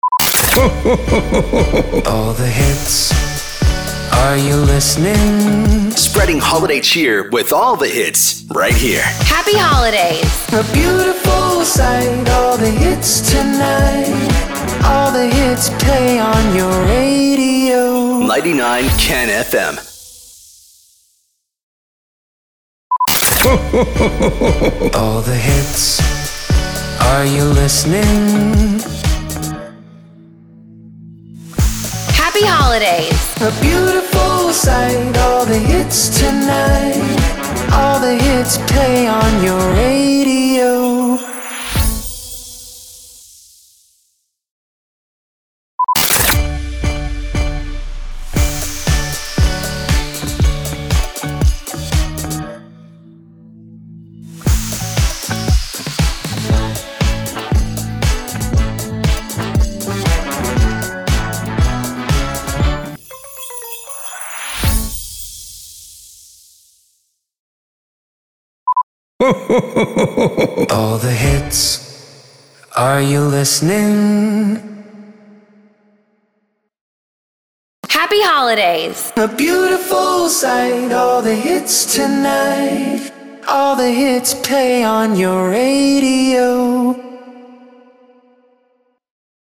768 – SWEEPER – CHRISTMAS
768-SWEEPER-CHRISTMAS.mp3